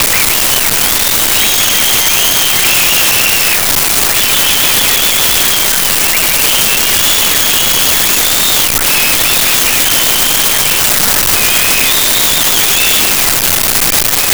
Whistles Crowd
Whistles Crowd.wav